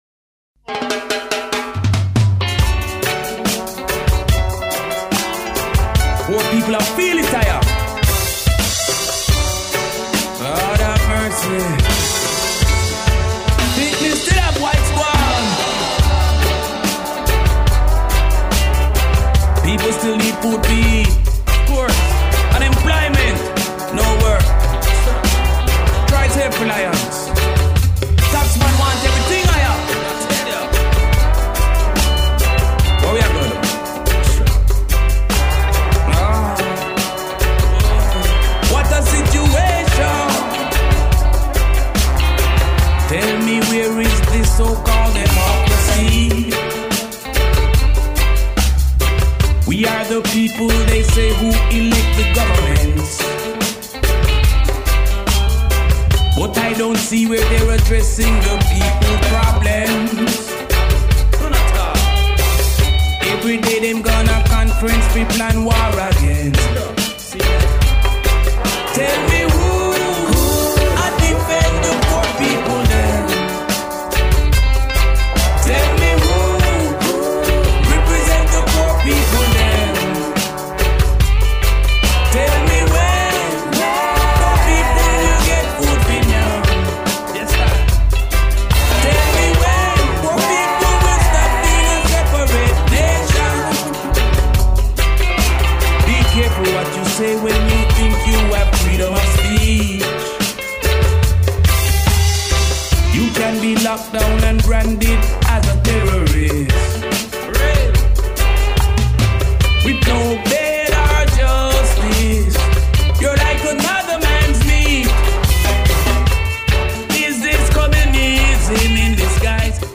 New riddim